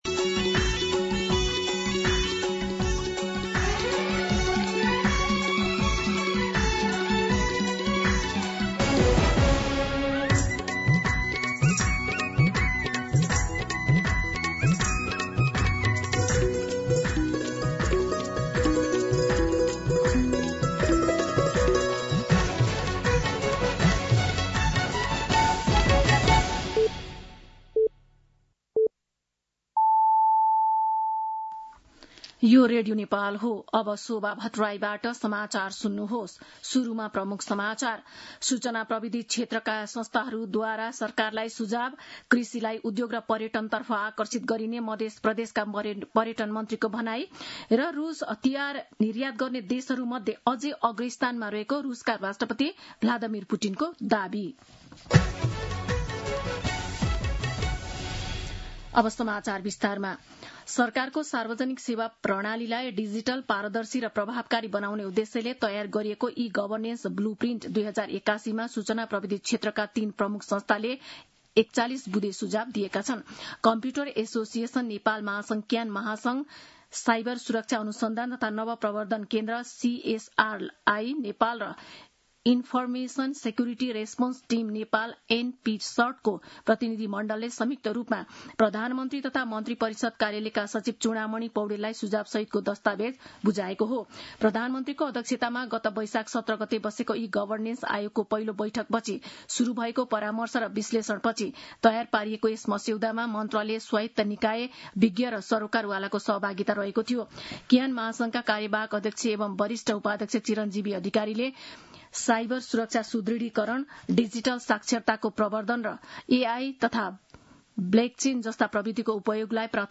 दिउँसो ३ बजेको नेपाली समाचार : १० जेठ , २०८२
3-pm-Nepali-News-4.mp3